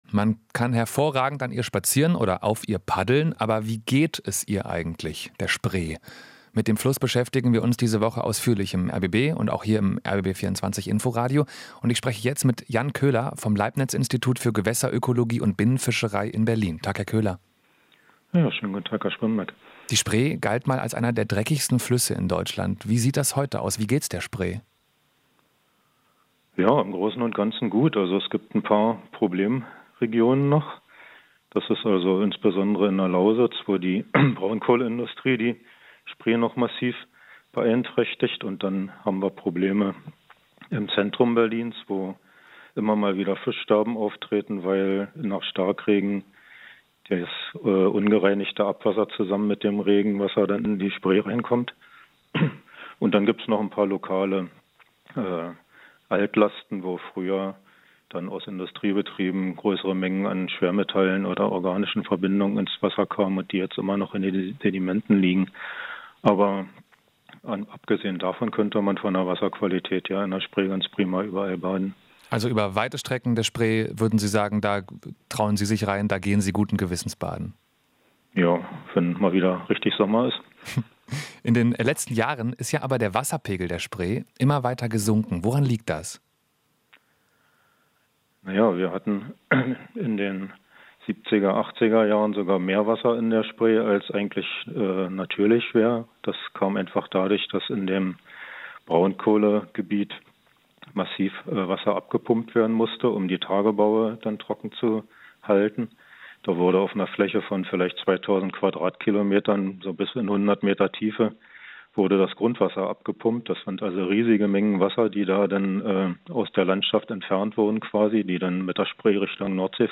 Interview - Ökologe: Spree braucht Wasser aus Pumpen in der Lausitz